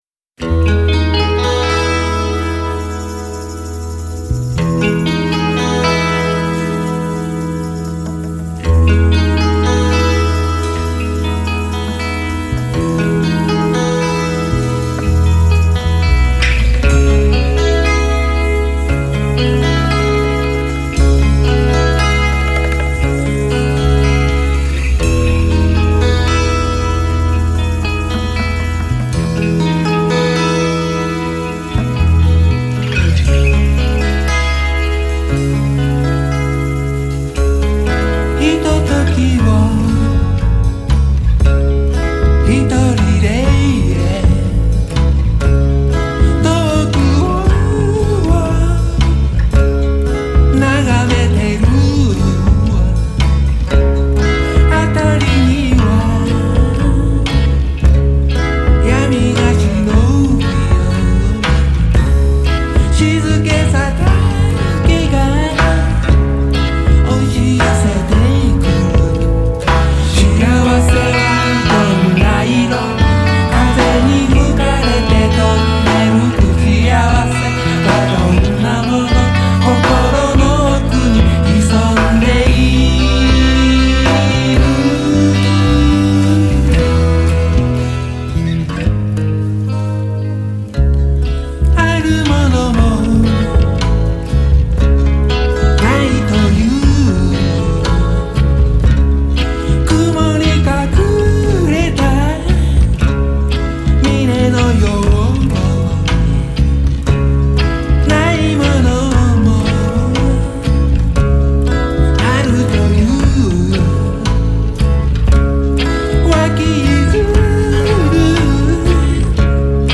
ピュアな感性で貫かれたフォーキーな楽曲には、甘酸っぱさを多分に含んだメロウな感触があり、独特の雰囲気を持っていますね！
永遠に枯れないフォーク・ミュージック！